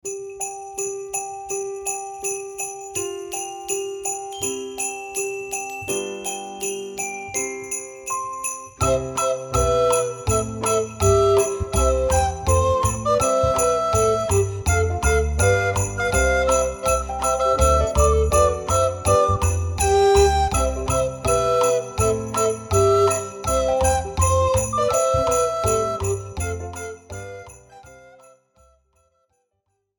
Besetzung: 1-2 Altblockflöten